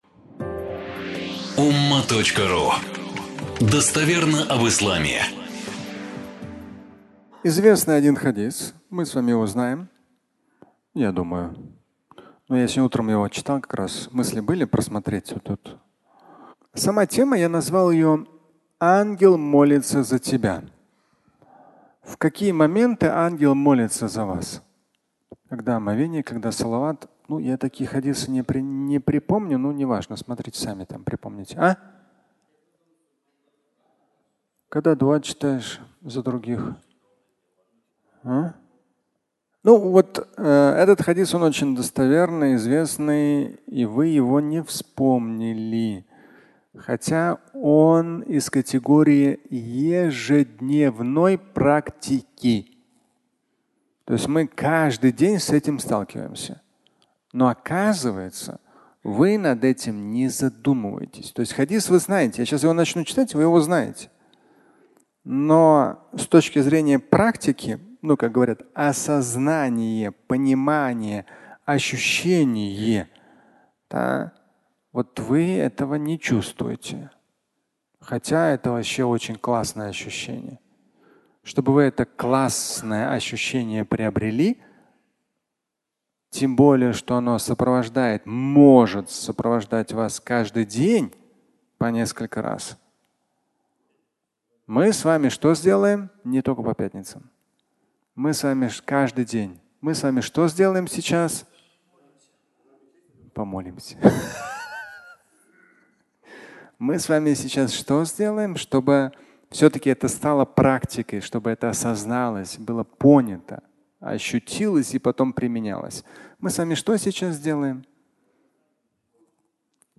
Фрагмент пятничной лекции